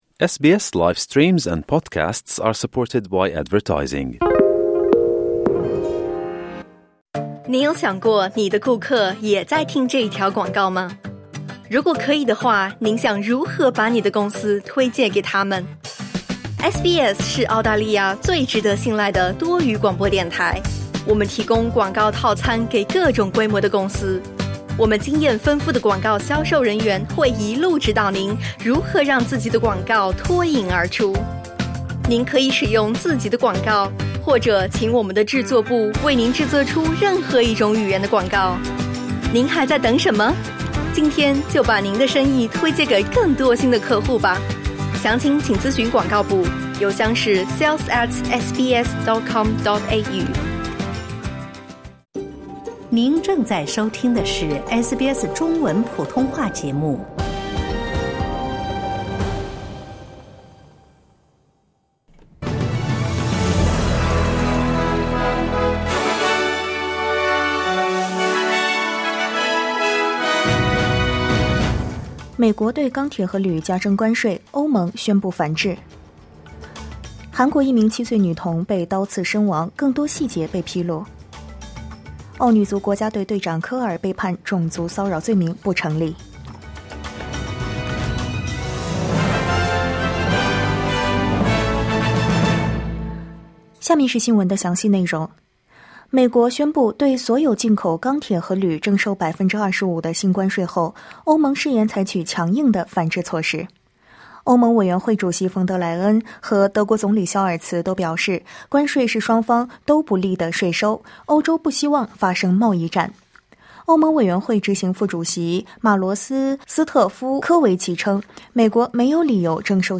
SBS早新闻（2025年2月12日）